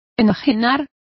Complete with pronunciation of the translation of alienated.